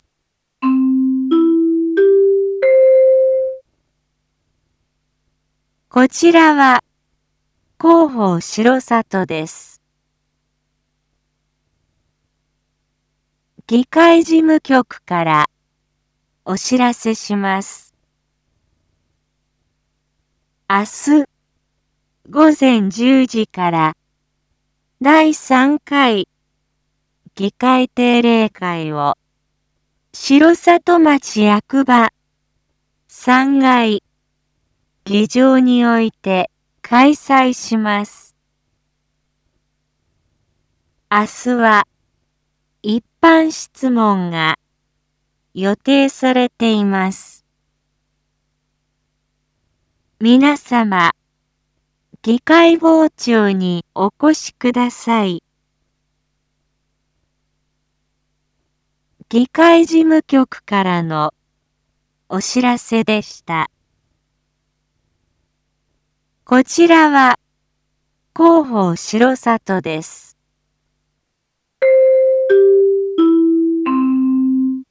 一般放送情報
Back Home 一般放送情報 音声放送 再生 一般放送情報 登録日時：2023-09-12 19:01:16 タイトル：9/12 19時 第3回議会定例会 インフォメーション：こちらは広報しろさとです。